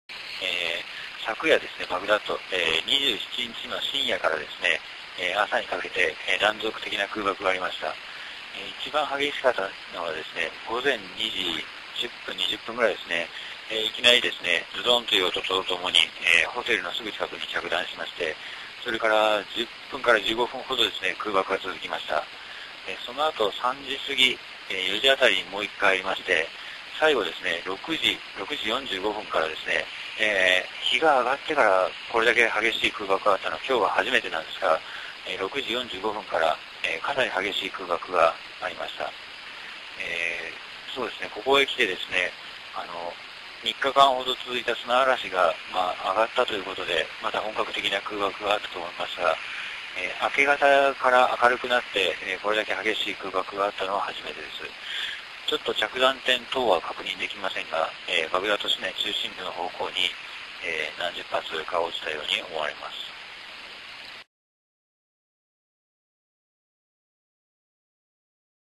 音声リポート